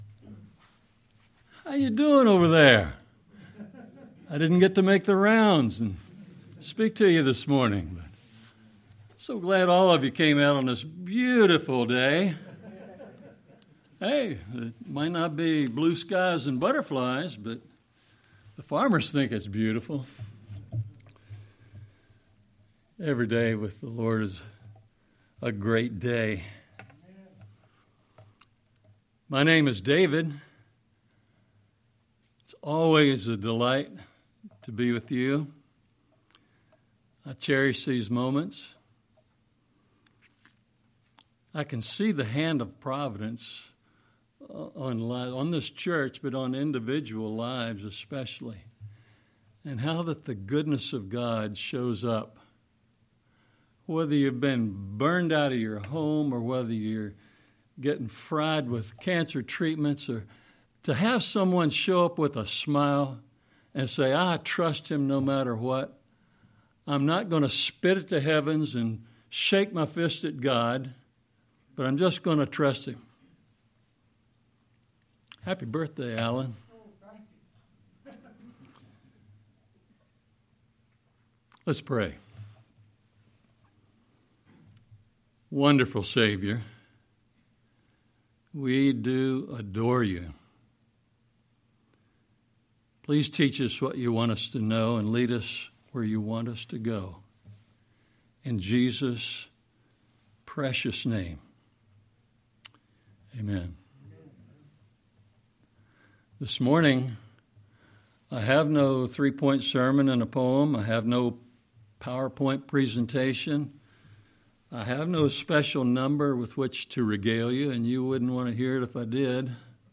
sermon-2-22-26.mp3